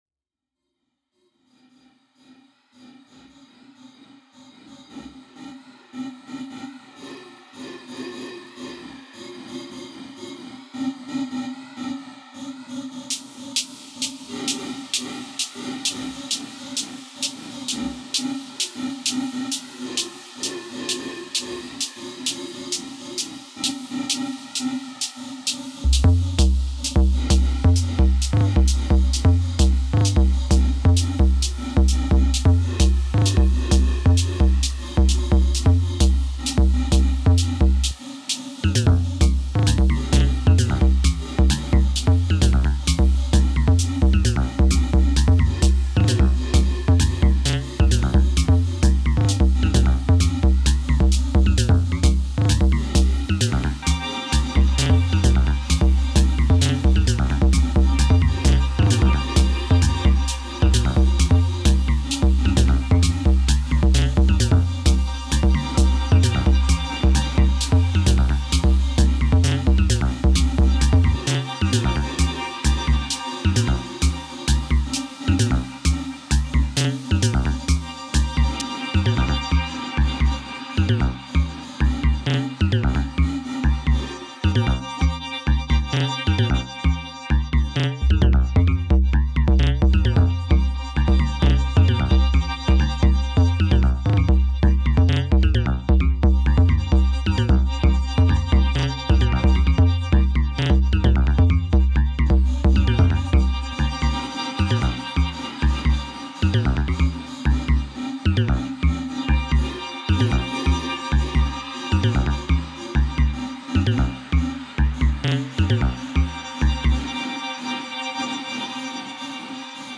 Direct record out of Opal in Ableton, with some quick “mastering”.